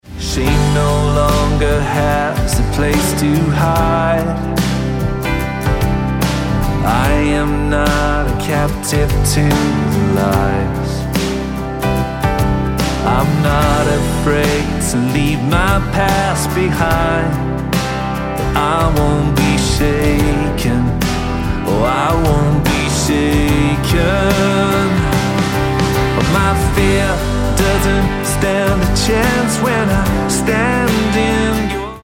Dm